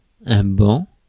Ääntäminen
France: IPA: [ɛ̃ bɑ̃]